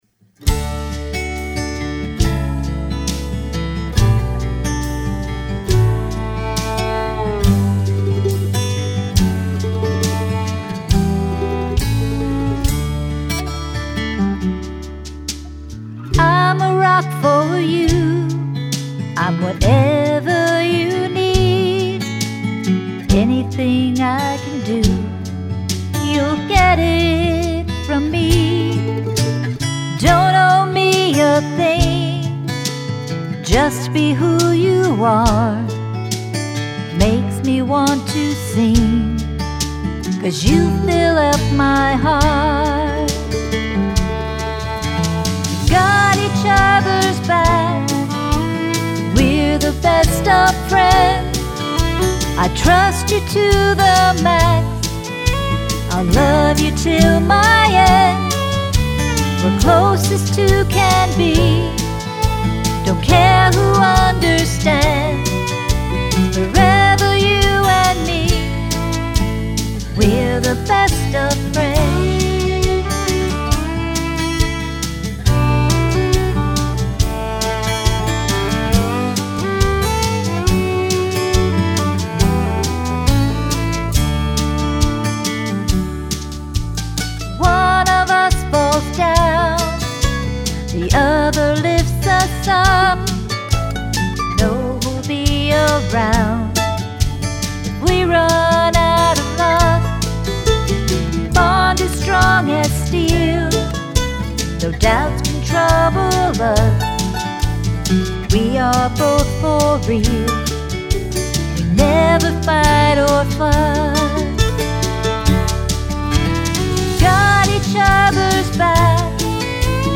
Complete Demo Song, with lyrics and music: